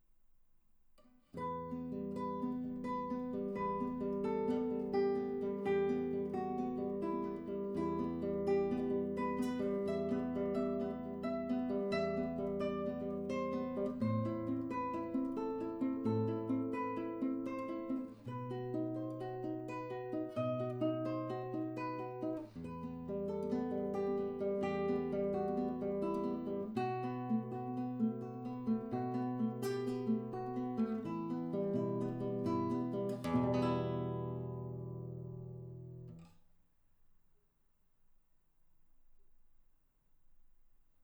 Here’s the promised recorded audio sample of the Motu M2 with T.Bone SC-1100 mic.
No processing. Mic input gain knob at about half-way.
By the way, the room has bad acoustics and there’s some background noises (such as a computer fan, some very light coil whining from a power supply, etc), but I don’t think the mic picked much of that.
The recording sounds nice, maybe next you want to get two identical mics and try your hand at some stereo recordings.
As for your recordings, I think you may have some USB noise in there.